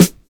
DISCO 13 SD.wav